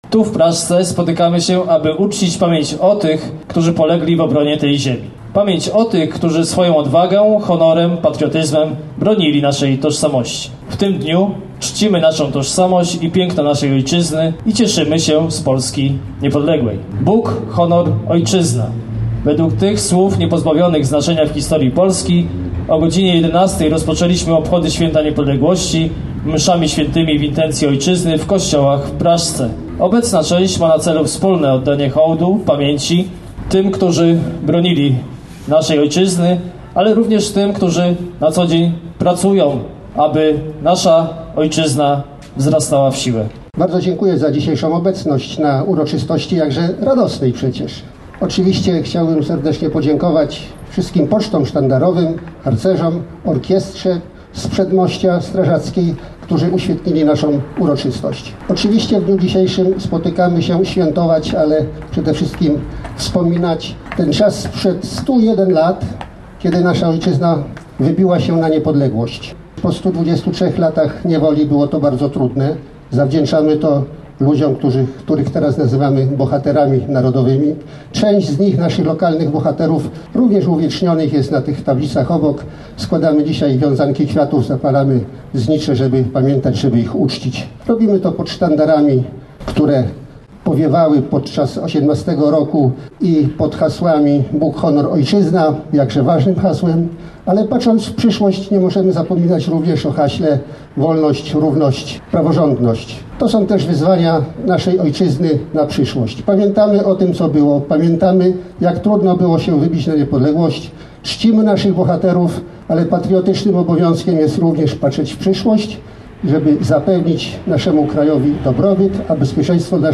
Tak przedstawiały się tegoroczne akcenty Święta Niepodległości w Praszce.
Oprawę muzyczną tej części obchodów zapewniła orkiestra dęta OSP z Przedmościa, a w dalszej części odbywającej się już w sali widowiskowej Miejskiego Domu Kultury, wystąpił także zespół Prosna.